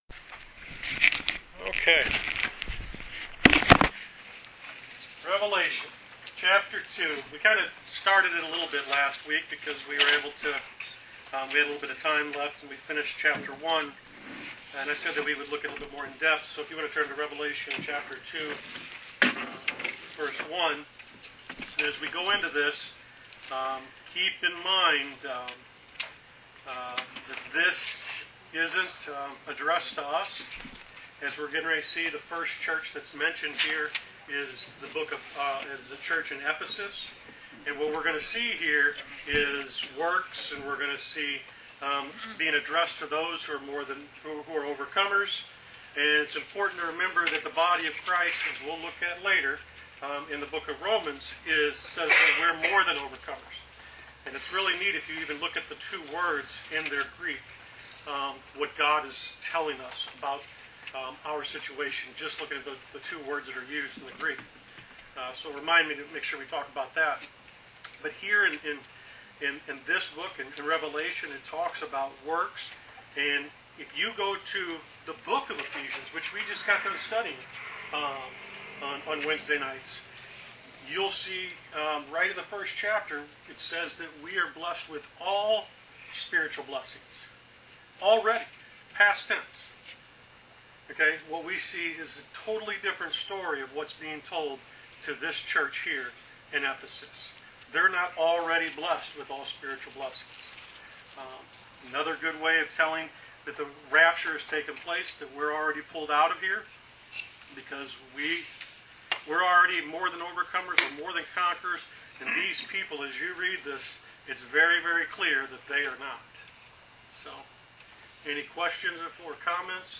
Wednesday Bible Study: Rev Ch 2